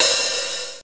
Tuned hi hats Free sound effects and audio clips
• Ambient Open Hat Sound A# Key 11.wav
Royality free open high-hat sound tuned to the A# note. Loudest frequency: 7026Hz
ambient-open-hat-sound-a-sharp-key-11-FIi.wav